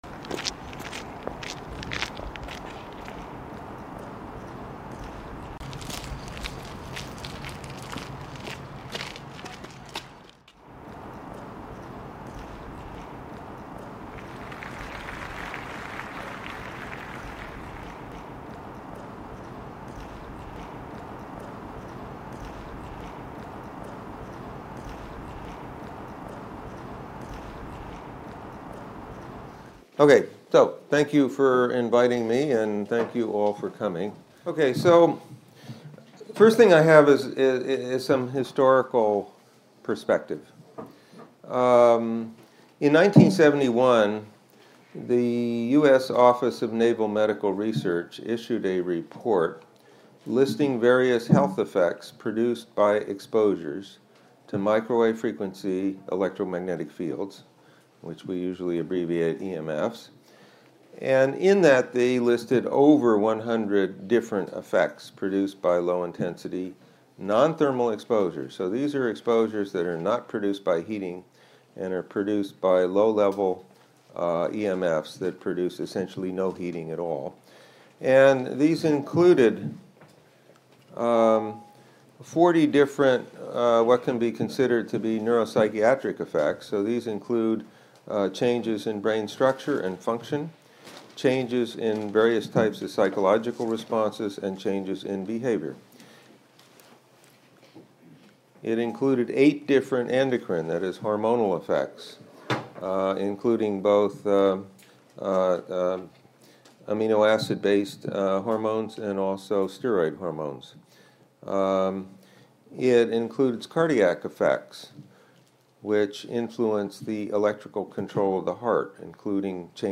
This video is footage from the High School GIH on 10th of March 2016 in Stockholm, Sweden.